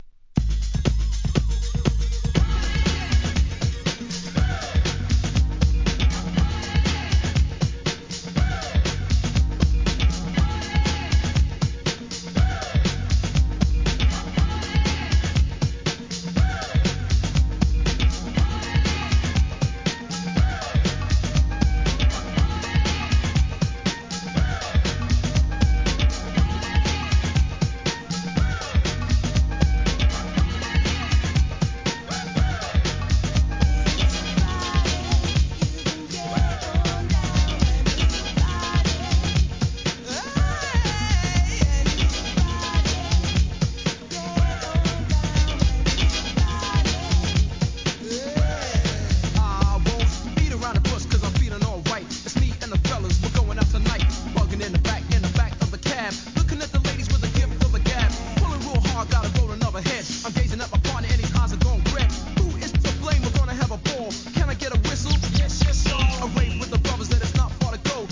HIP HOP/R&B
1992年、疾走感溢れるUK NEW SCHOOL!!